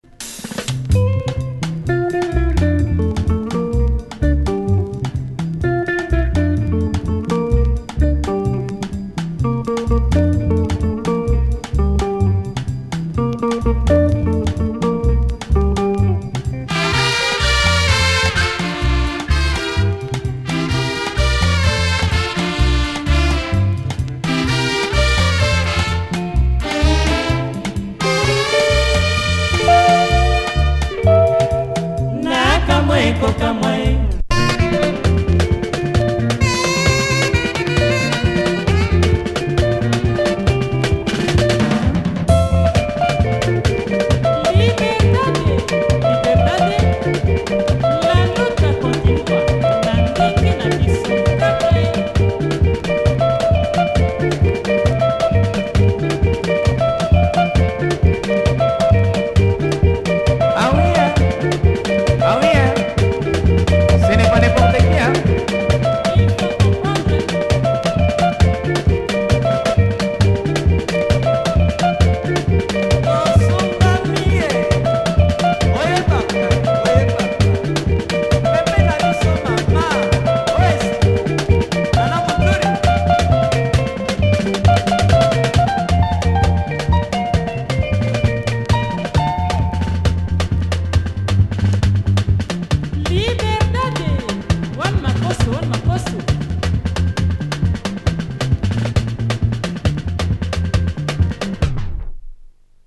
Nice Lingala.